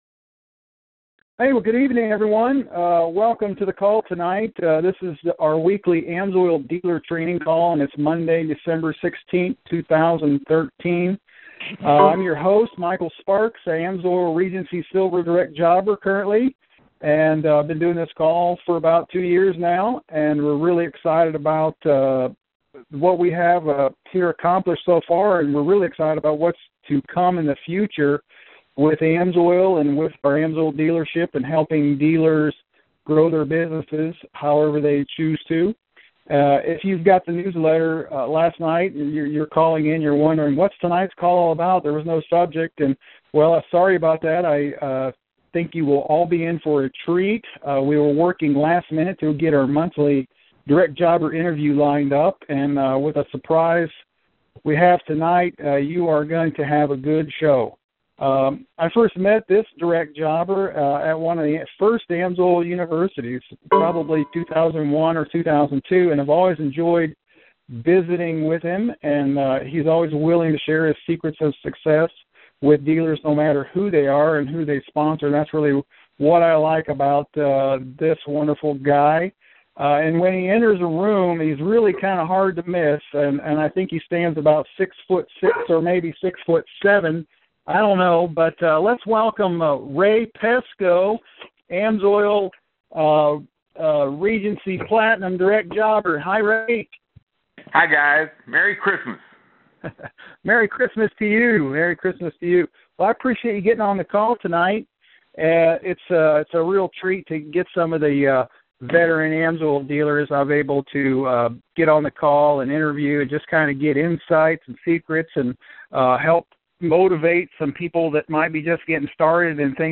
Listen in to our weekly AMSOIL Dealer training call.